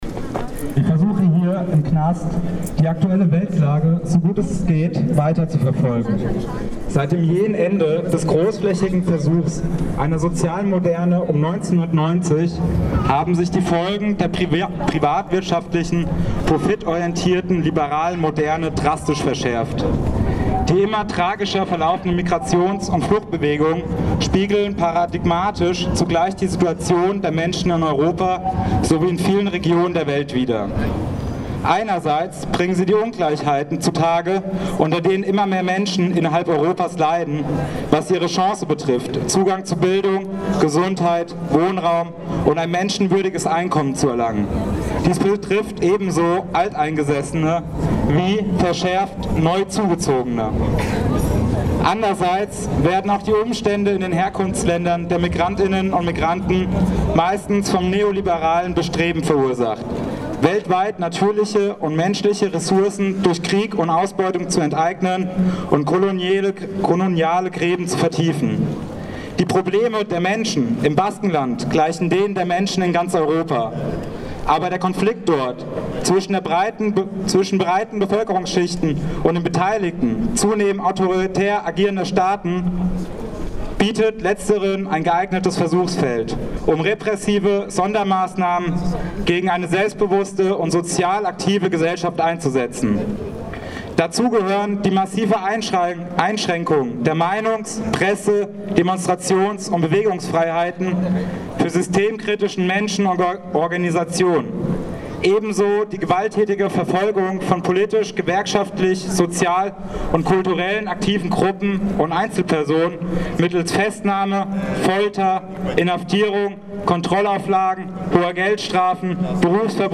Kundgebung in Freiburg
Auschnitt aus dem Audio-Mitschnitt von RDL